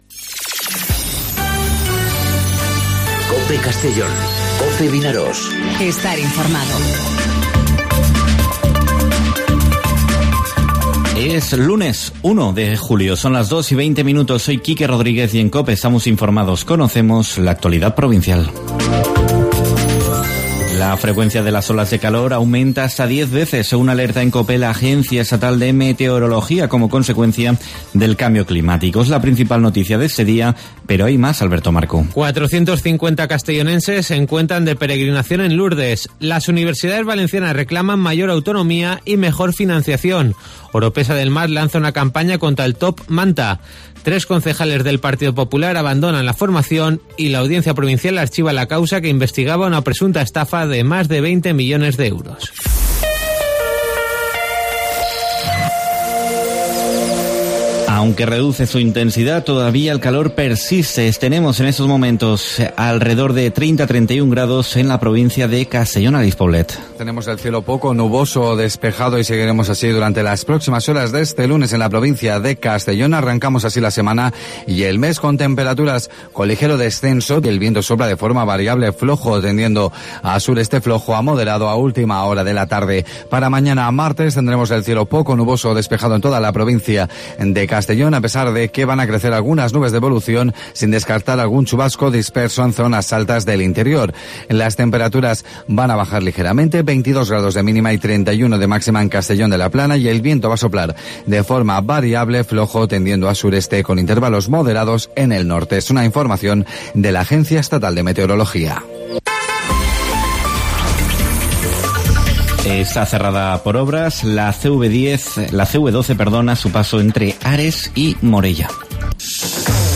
Informativo 'Mediodía COPE' en Castellón (01/07/2019)